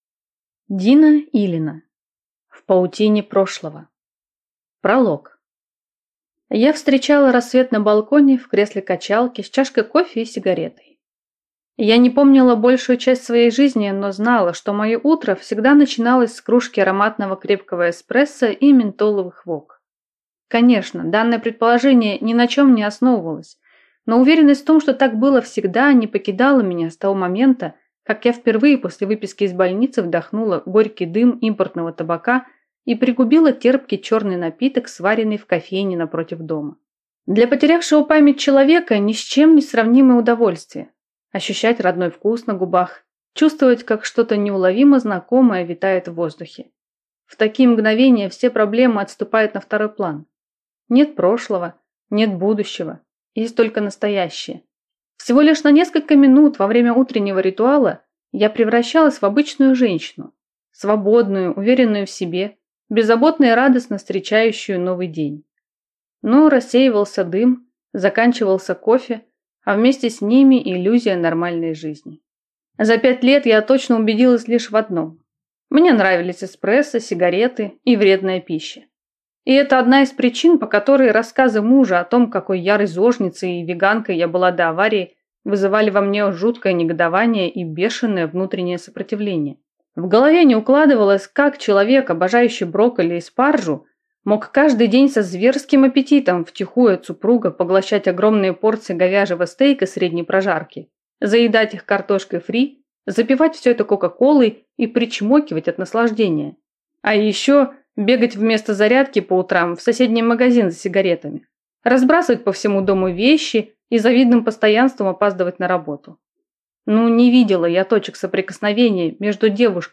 Аудиокнига В паутине прошлого | Библиотека аудиокниг